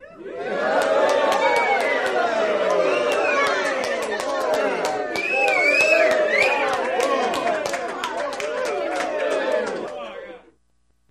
Crowd Cheer Small Amazed With Single Claps